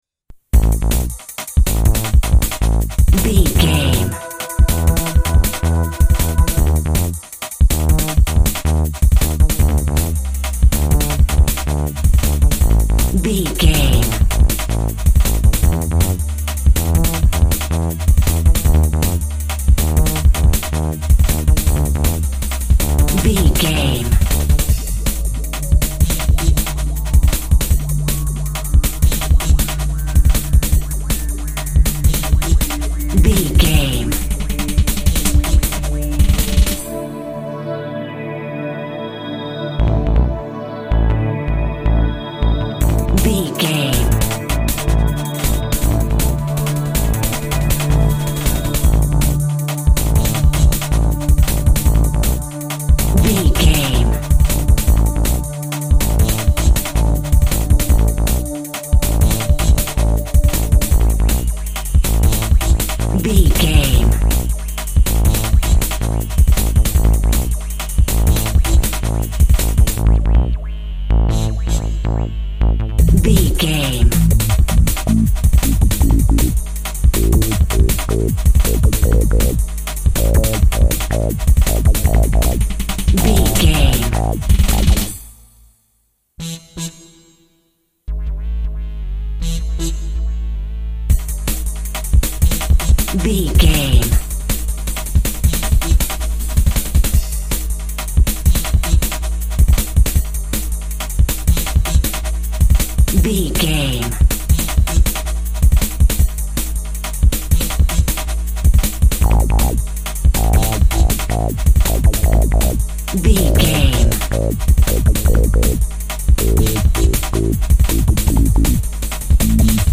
Epic / Action
Fast paced
Aeolian/Minor
Fast
intense
energetic
house
electronic
techno
synth lead
synth bass
electronic drums
Synth Pads